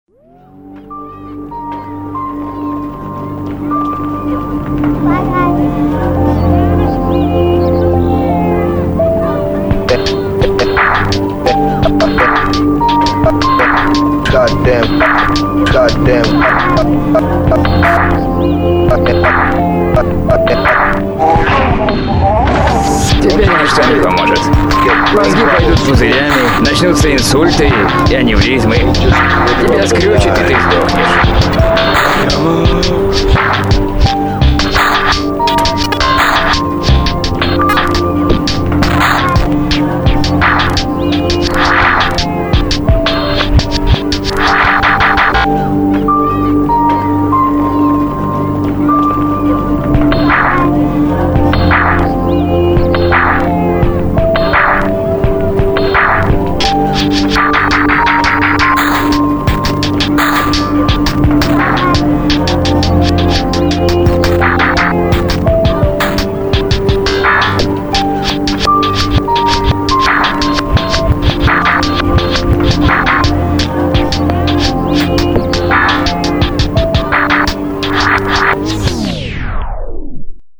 • Жанр: Романс